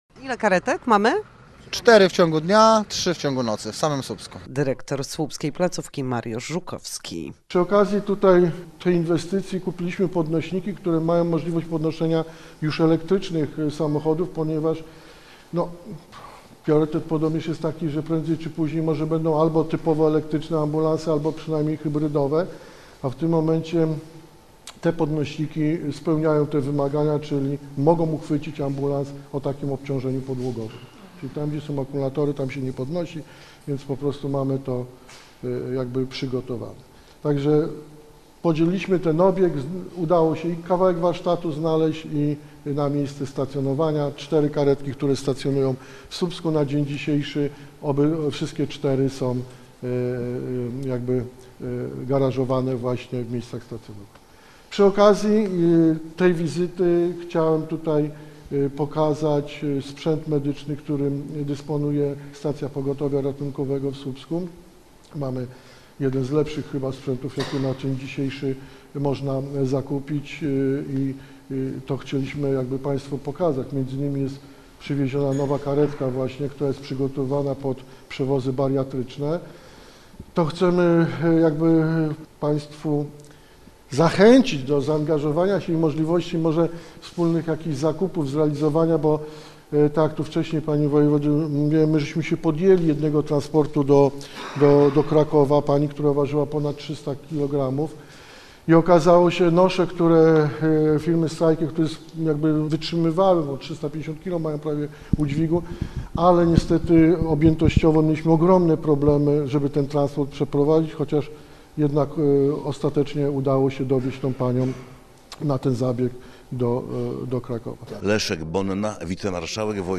Posłuchaj materiału reporterki: https